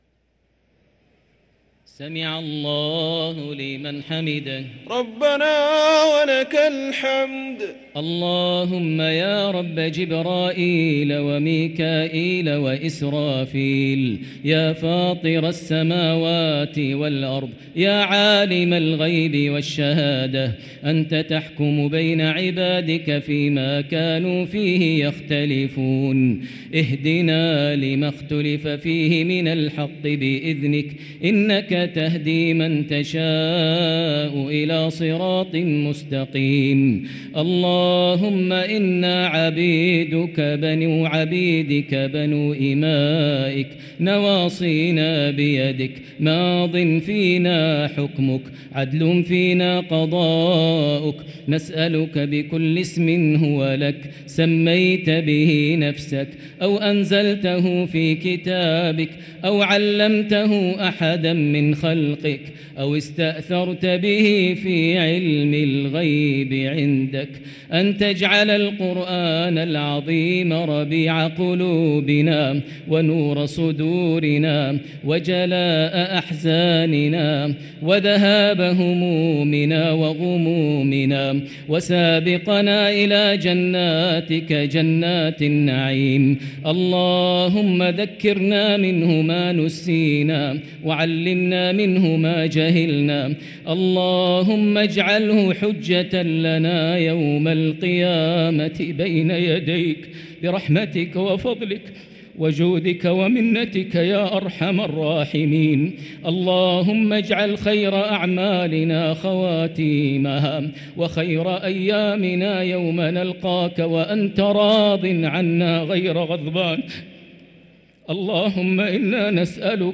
| Dua for the night of 17 Ramadan 1444H > Taraweh 1444H > Taraweeh - Maher Almuaiqly Recitations